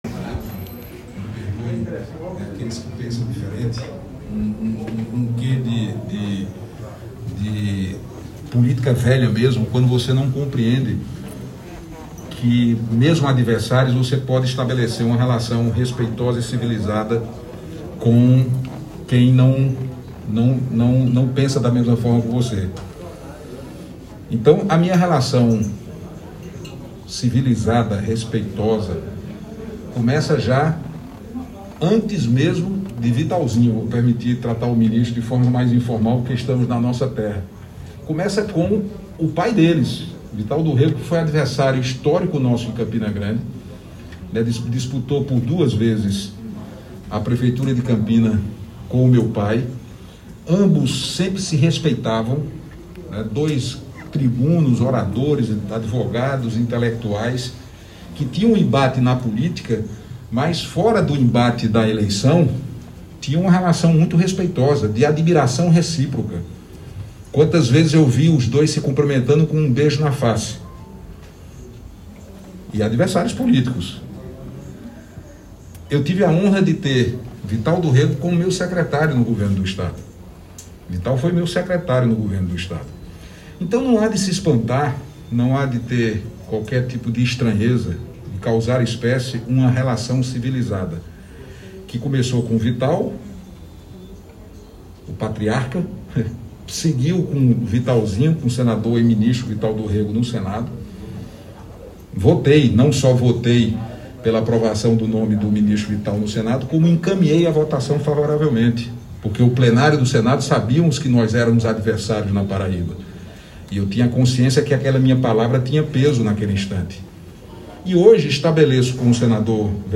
O ex-senador Cássio Cunha Lima (PSDB), falou sobre sua relação com o senador Veneziano Vital do Rêgo (MDB), durante entrevista coletiva nesta quinta-feira (29). Cássio afirmou que mantém uma boa relação com Veneziano desde quando eles eram adversários políticos em Campina Grande e agradeceu ao senador Veneziano publicamente, que sempre o recebeu com muita educação, quando procurado.